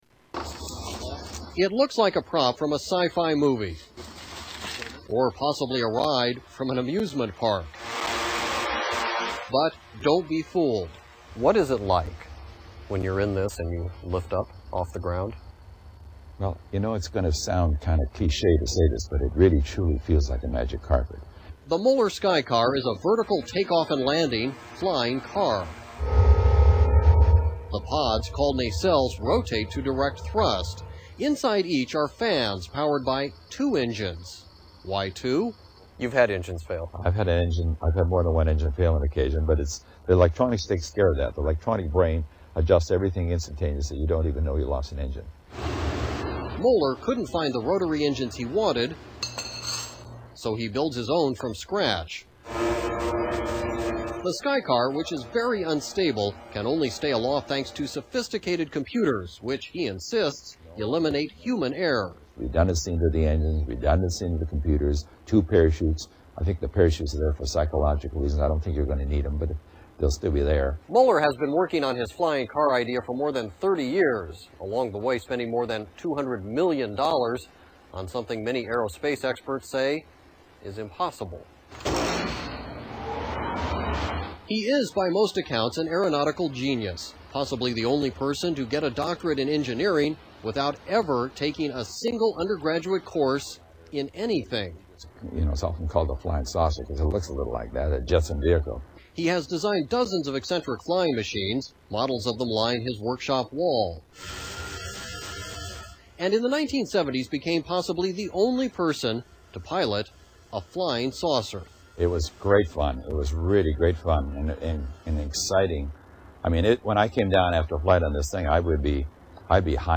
Interview 8